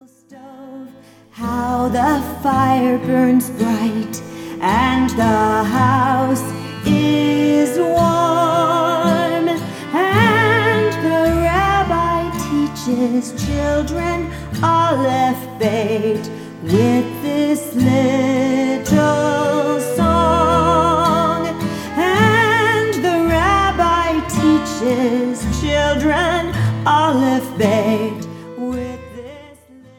From upbeat and hand-clapping to soothing and relaxing